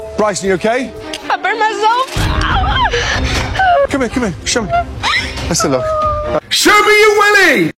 show me your willy Meme Sound Effect